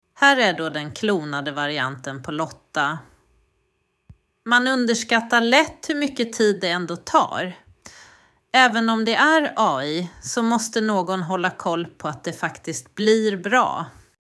Här kan du lyssna på inspelad människoröst, klonad röst och AI-röst